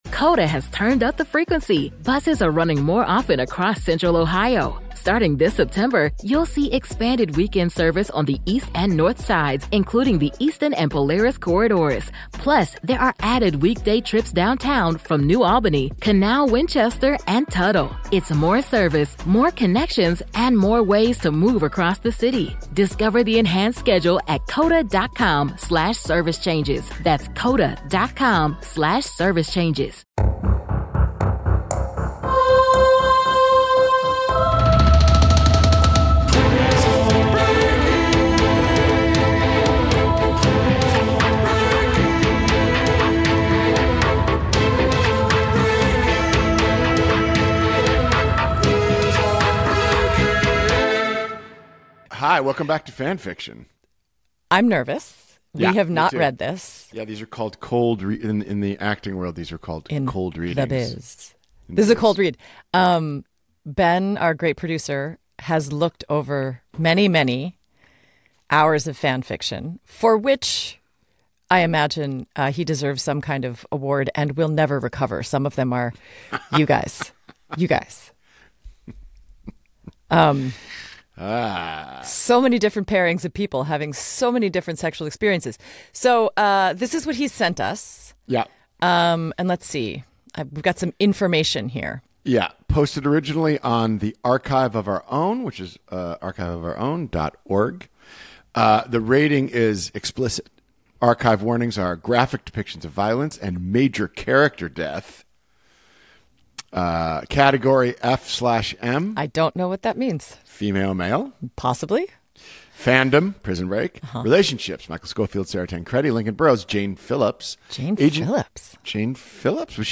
This one has it all, so grab a glass of your favorite beverage, slip on your favorite slippers, and crank up the podcast volume because Sarah Wayne Callies and Paul Adelstein are ready to tell you some Prison Break Fan Fiction!